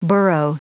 Prononciation du mot borough en anglais (fichier audio)
Prononciation du mot : borough